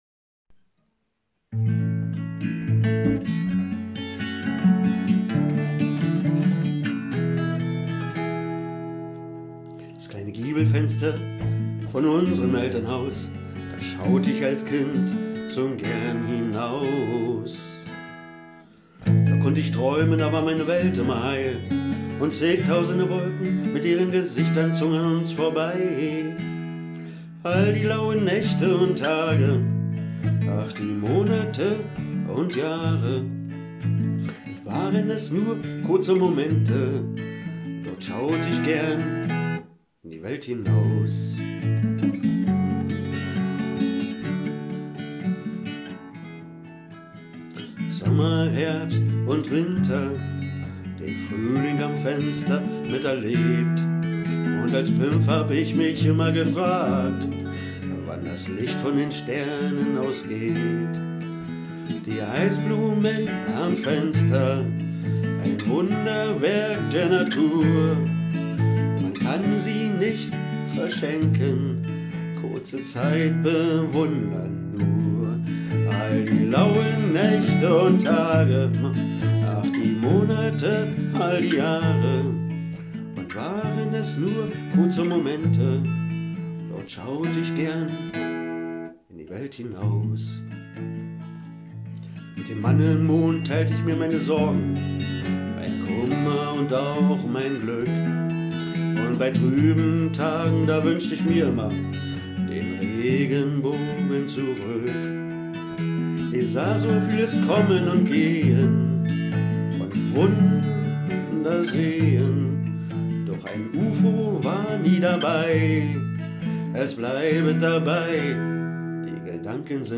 Mit seiner Akustikgitarre und ausdrucksstarken Stimme präsentiert er eine vielseitige Mischung aus eigenen, deutschsprachigen Songs – mal nachdenklich und tiefgehend, mal leicht und schwungvoll.